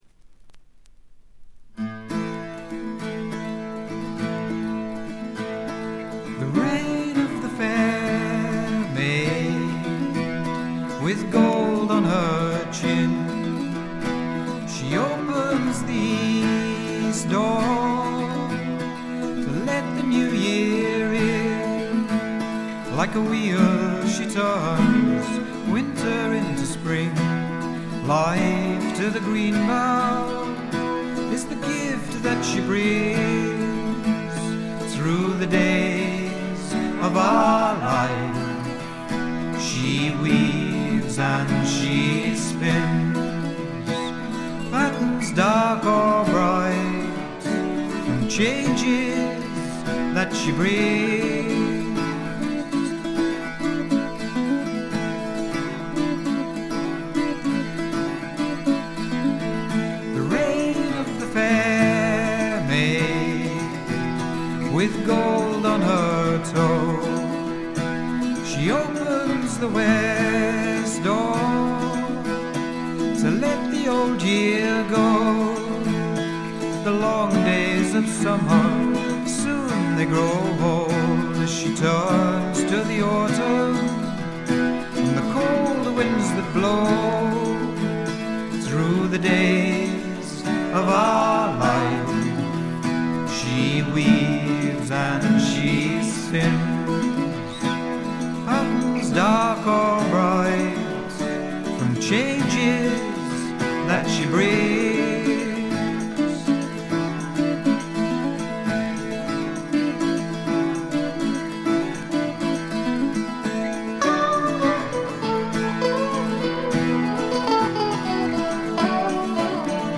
ホーム > レコード：英国 フォーク / トラッド
試聴曲は現品からの取り込み音源です。
Electric Guitar, Drums [Linn], Backing Vocals